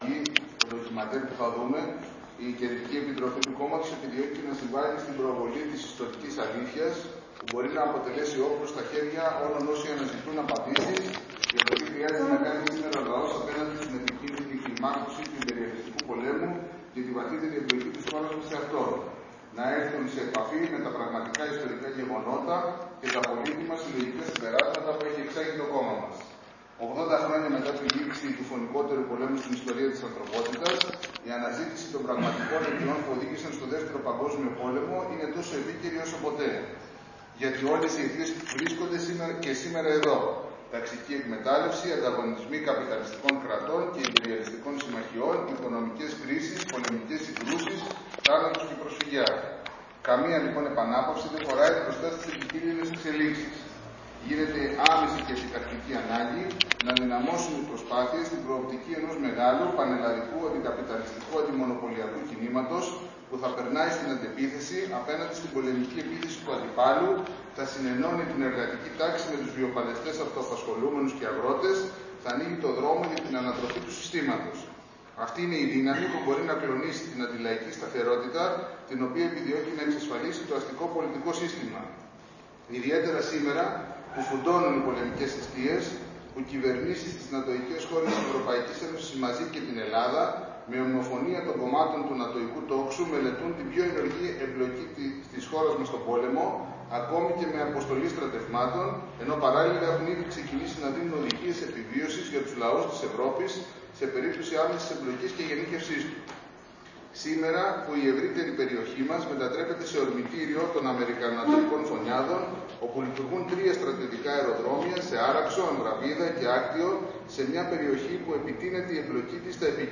Ένα ντοκιμαντέρ της Κεντρικής Επιτροπής του ΚΚΕ για 80 χρόνια από την Αντιφασιστική Νίκη των λαών.